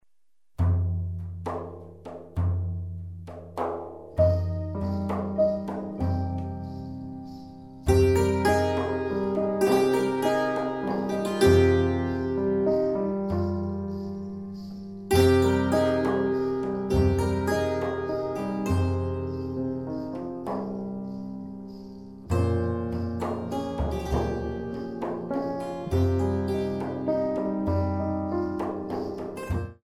3/4  mm=100